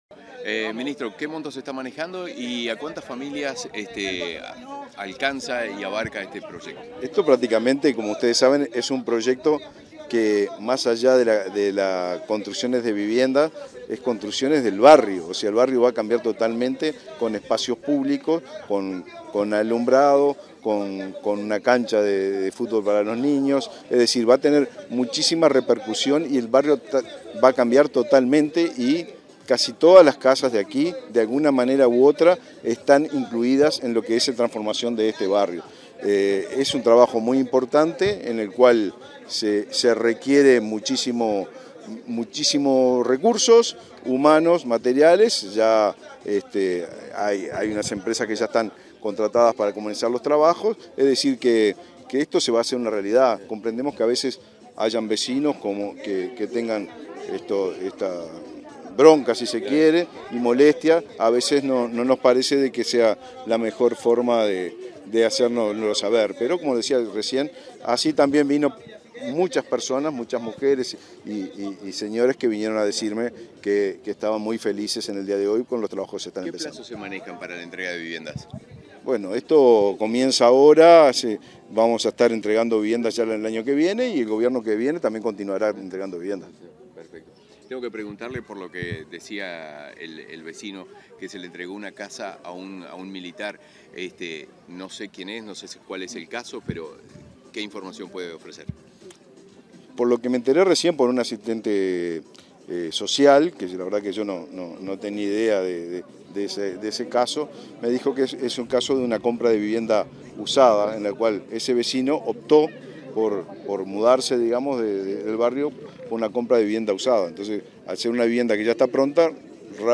Declaraciones a la prensa del ministro del MVOT, Raúl Lozano, en el barrio Maracaná Sur
Tras participar en el acto de inicio de construcción de 200 soluciones habitacionales en el barrio Maracaná Sur, en Montevideo, este 8 de diciembre,